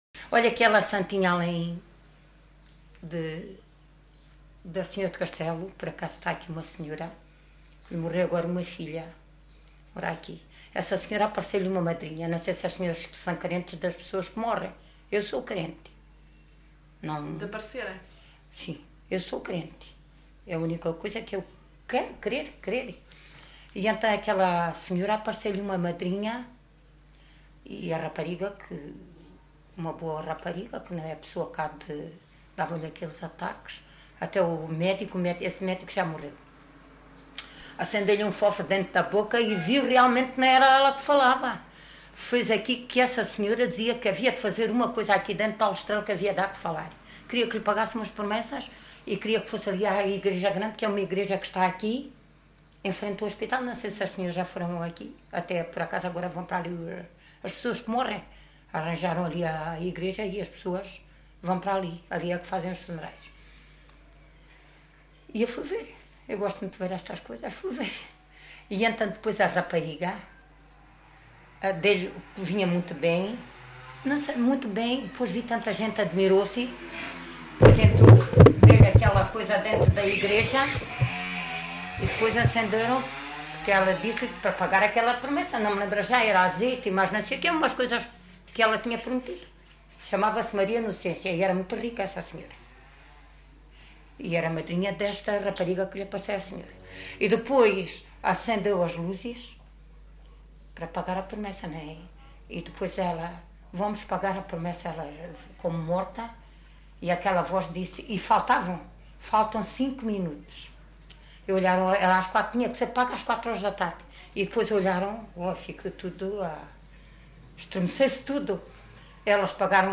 LocalidadeAljustrel (Aljustrel, Beja)